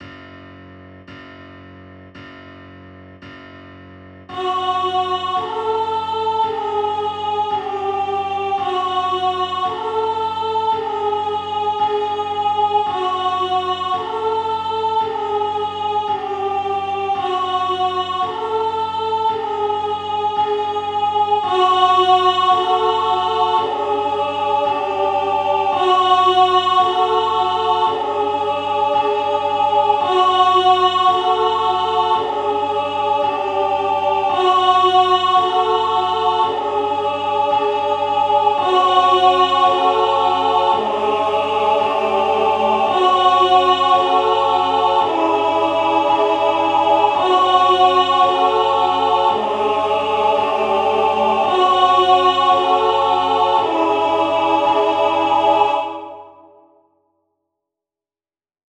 • La ville haute – Pédale de boucle